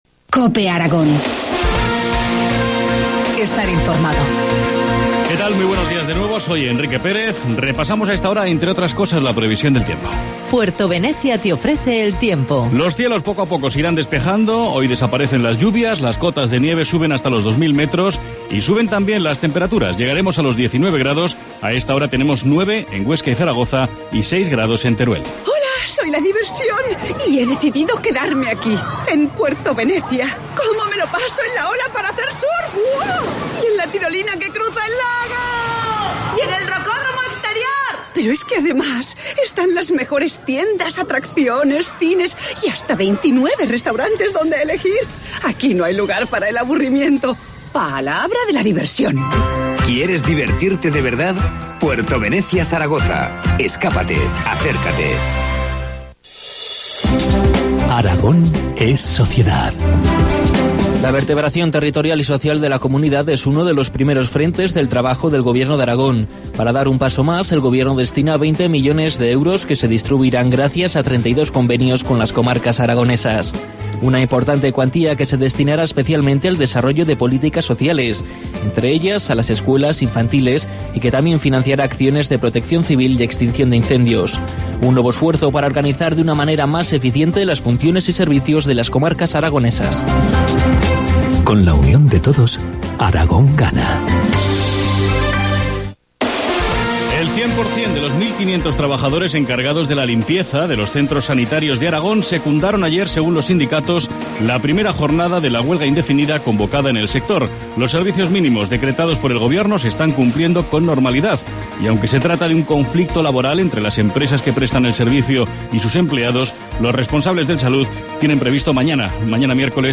Informativo matinal, martes 21 de mayo, 8.25 horas